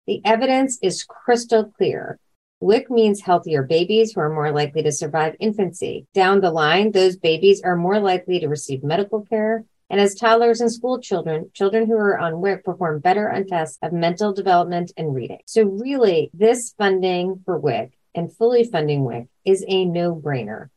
Neera Tanden, Director of the Domestic Policy Council, says WIC is a vital program for many.
Audio with Neera Tanden, Director of the Domestic Policy Council, and Xochitl (so-CHEEL) Torres Small, Deputy Secretary of the USDA